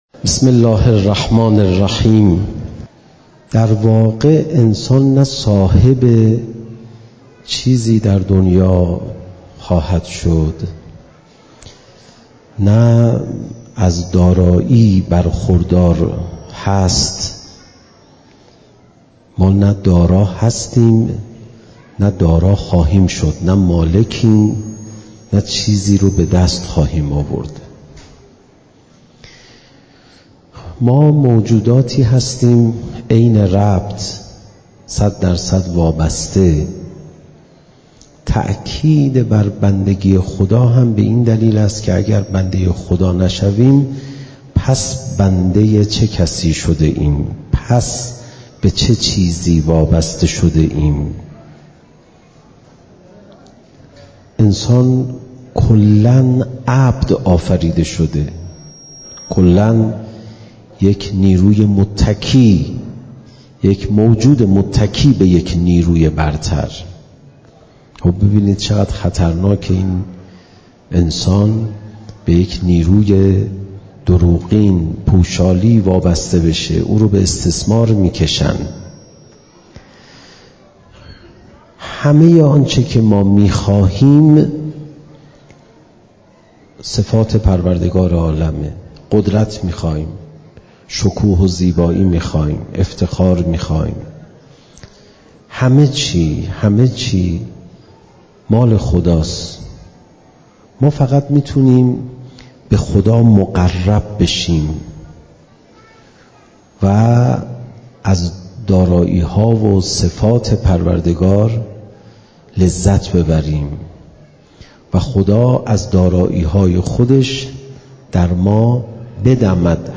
زمان: 51:17 | حجم: 12.0 MB | مکان: آستان مقدس حضرت صالح (علیه السلام) - تهران | تاریخ: رمضان 1401ش